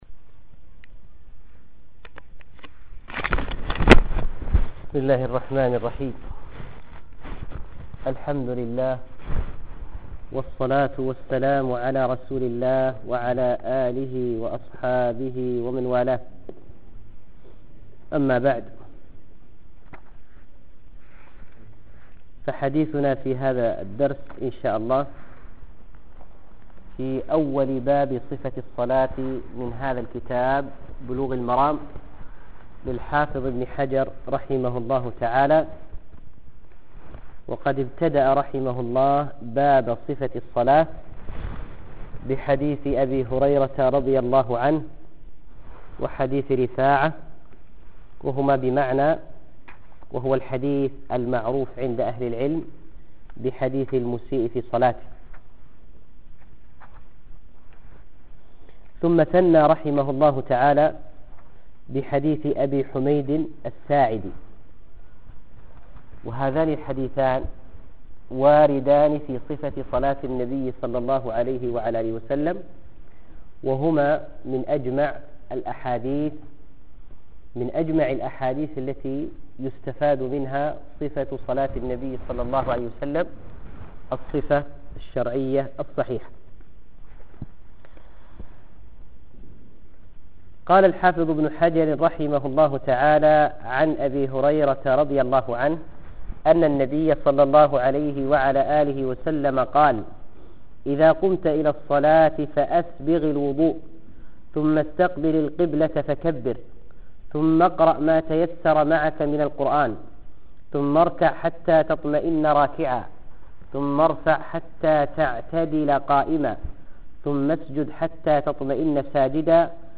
بلوغ المرام حديث المسيء الدرس رقم 35